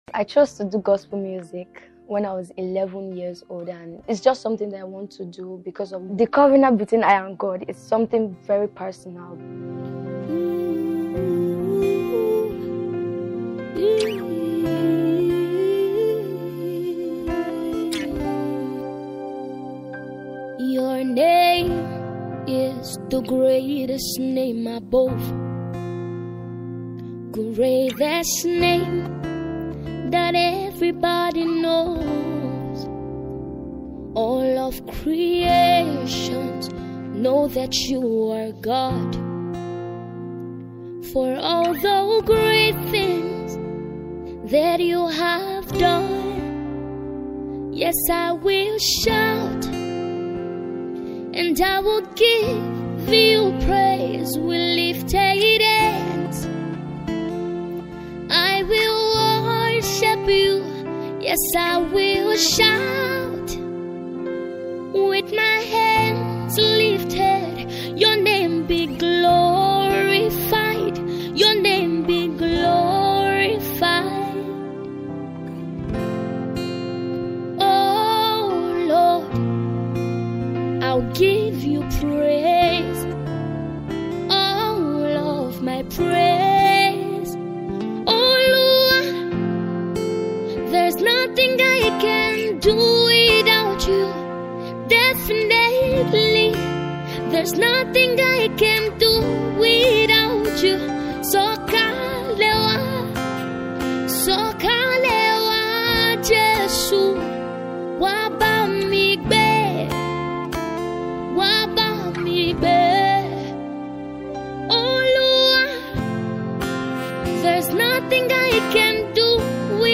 worship music
a spirit moving sound
contemporary Gospel